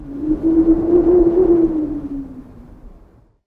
Strong Wind Gust
A powerful burst of wind that builds quickly and fades with a low howl
strong-wind-gust.mp3